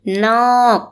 noorg